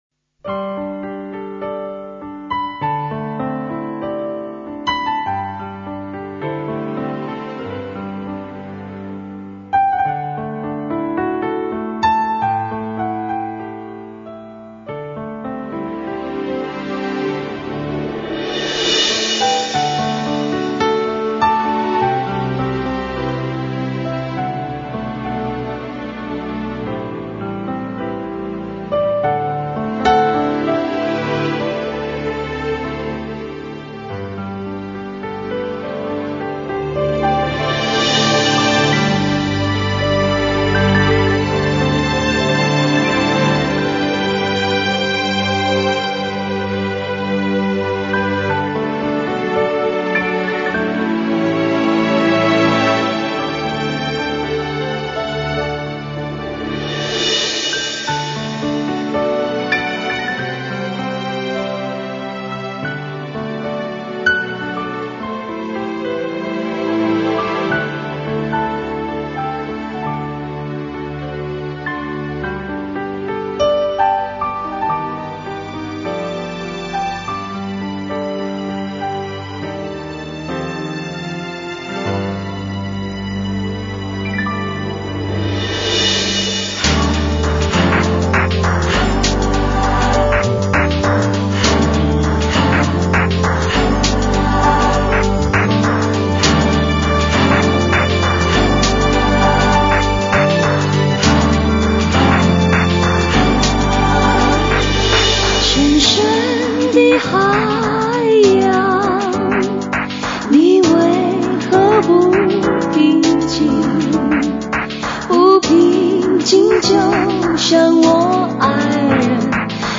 前南斯拉夫 歌曲